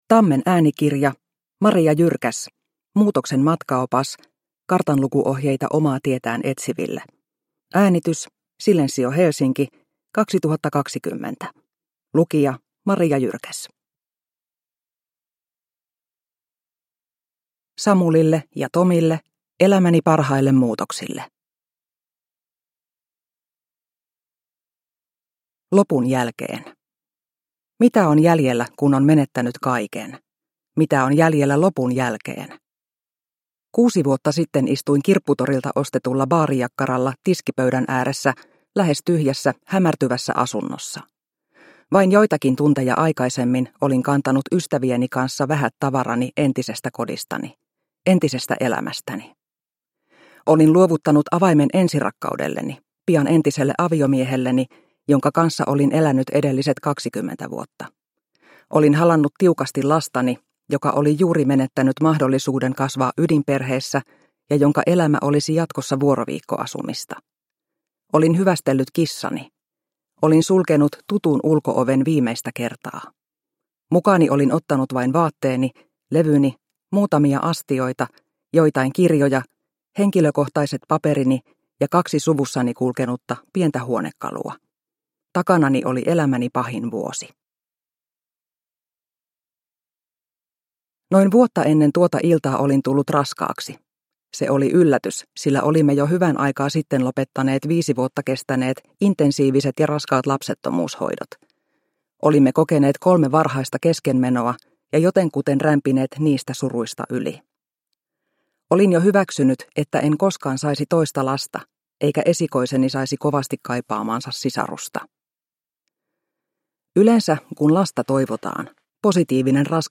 Muutoksen matkaopas – Ljudbok – Laddas ner